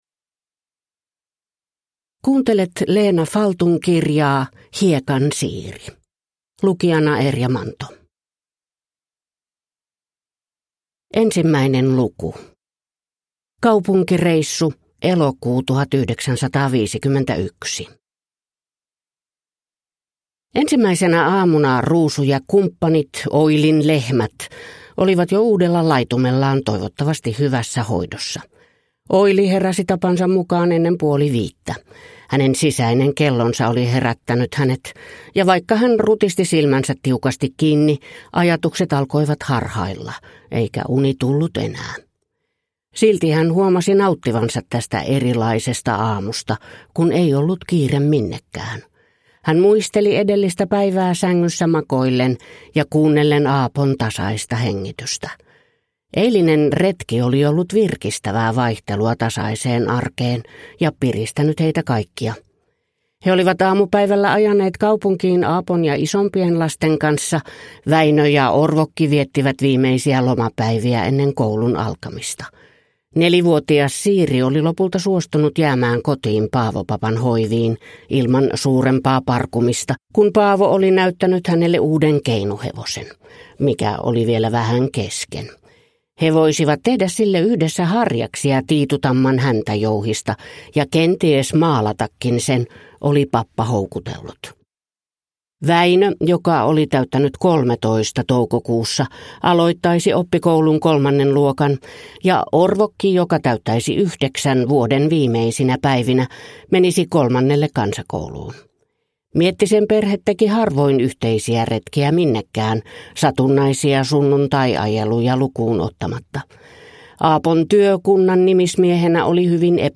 Hiekan Siiri – Ljudbok